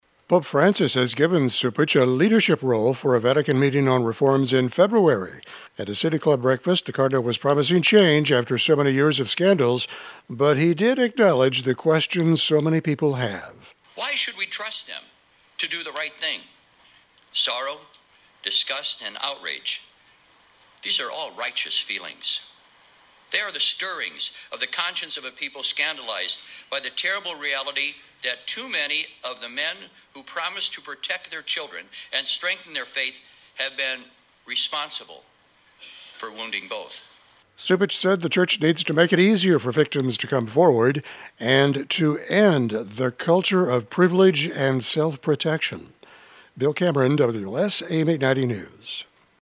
At a City Club breakfast, the cardinal was promising change after so many years of scandals, but he did acknowledge the questions so many people have.